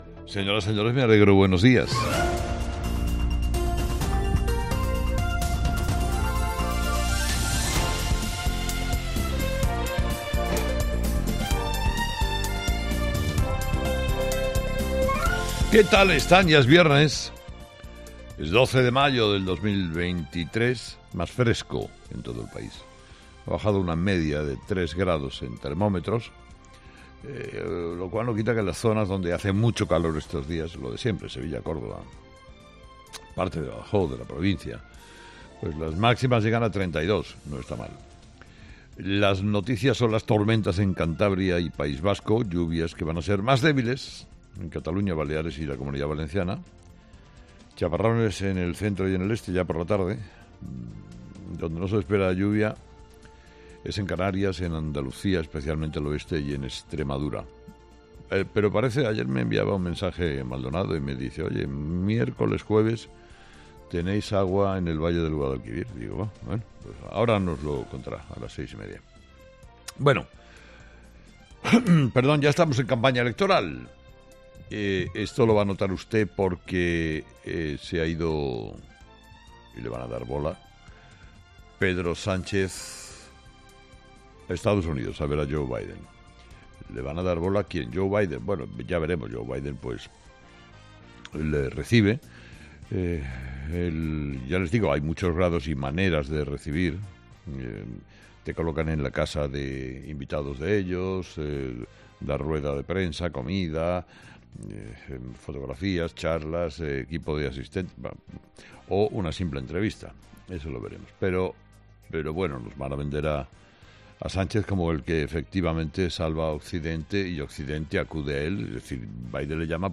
Escucha el análisis de Carlos Herrera a las 06:00 horas en Herrera en COPE este viernes 12 de mayo de 2023